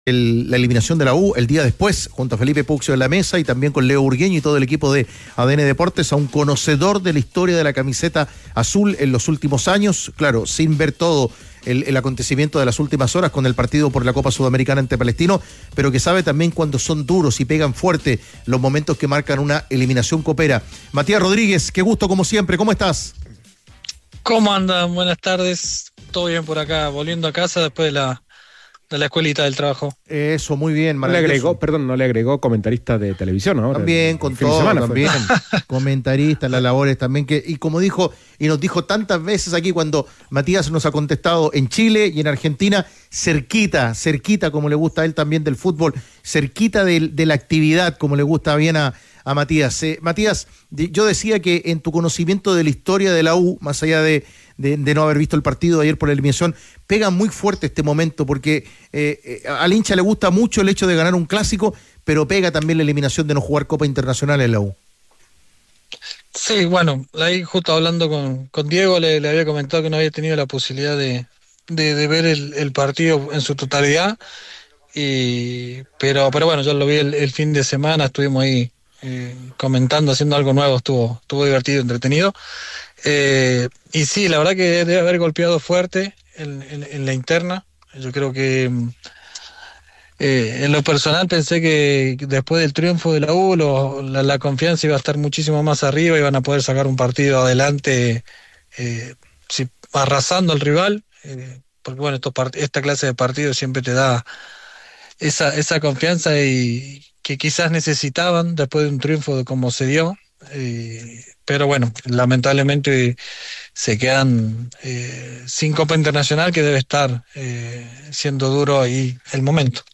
En conversación con Los Tenores de la Tarde, Matías Rodríguez, histórico lateral de la Universidad de Chile, abordó la cruda eliminación azul de la Copa Sudamericana.